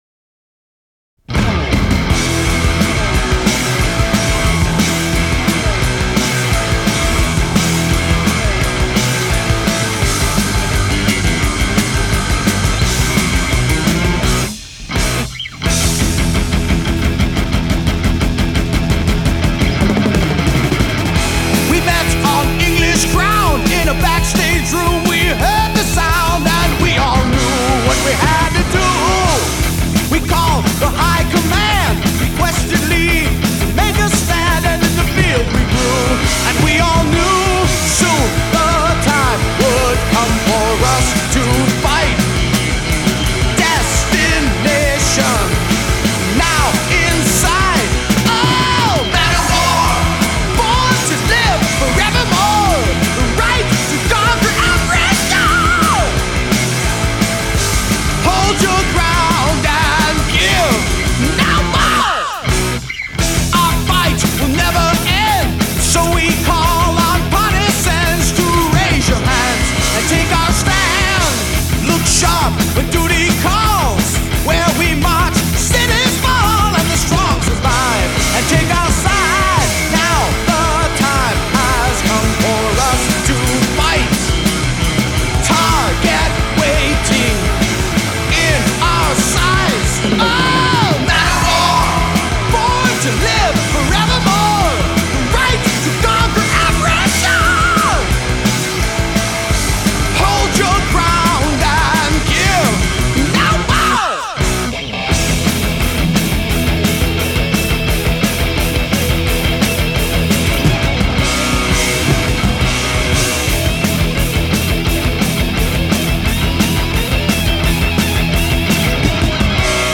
For me mostly hard rock, heavy metal and power metal.